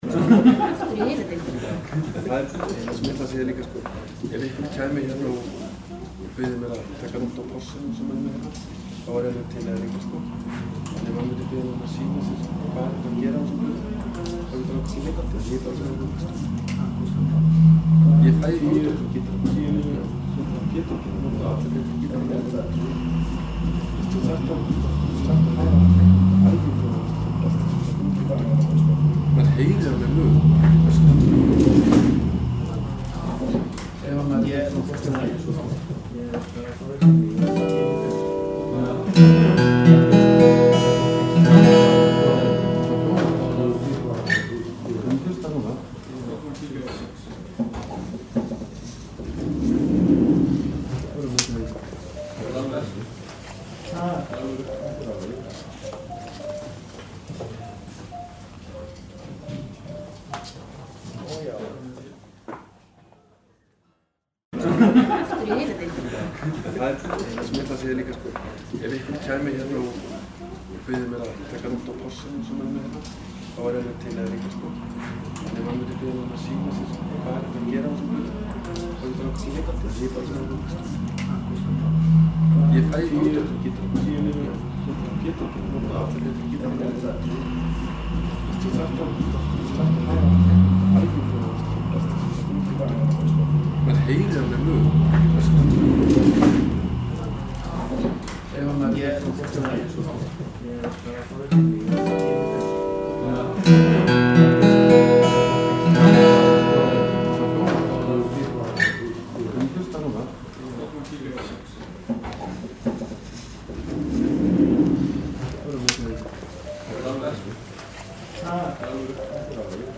The audible noise and sensitivity between these two mics was almost equal, but NTG3 have noticeably higher low frequency response.
The recorder was Sound devises 744 at 24bit/48Khz. NTG3 was in channel 1 and for comparison ME66 was in channel 2. They were laying side by side and the gain was in full position. Input filter for both channels was at 80Hz, 12dB/oct.
There is some strange white noise in NTG3.
First minute is NTG3 and the second is ME66.
ntg3_vs_me66.mp3